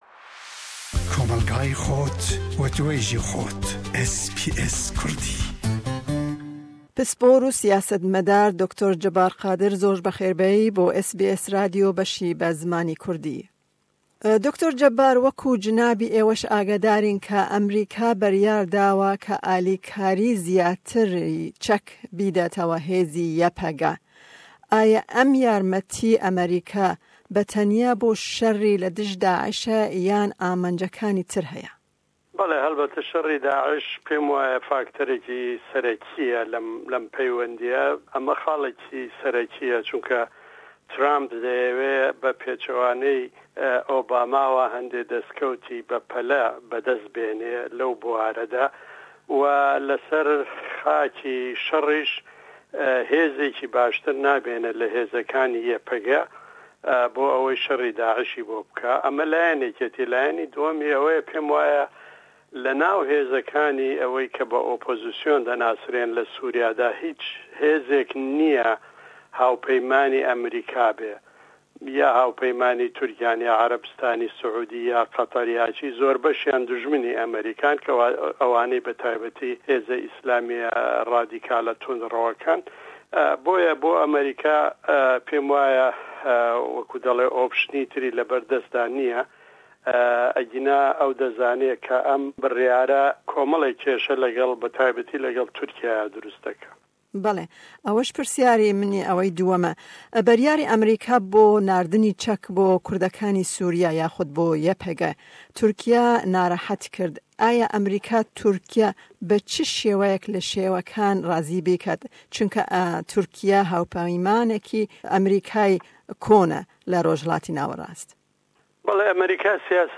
Me hevpeyvînek